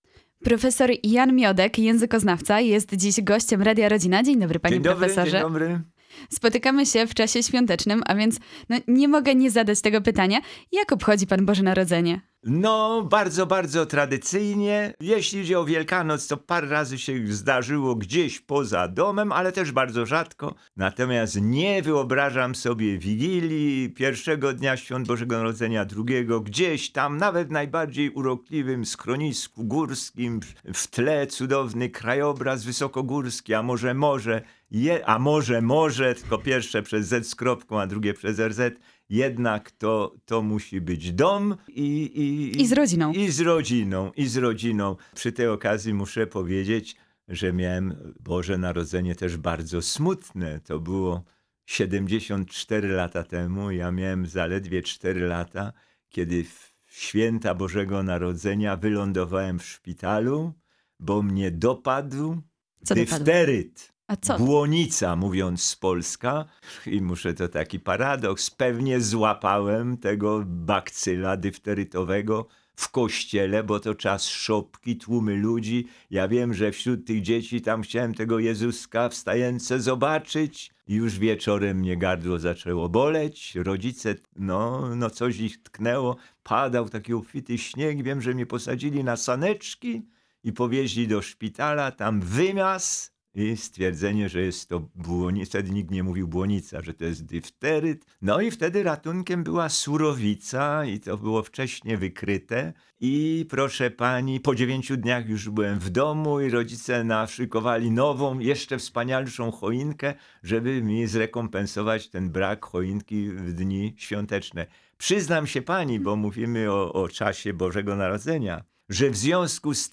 Językoznawca, prof. Jan Miodek odwiedził w pierwszy dzień świąt studio Radia Rodzina. W rozmowie opowiedział o korzeniach terminów związanych z Bożym Narodzeniem, a także o świętach, które pamięta z dzieciństwa.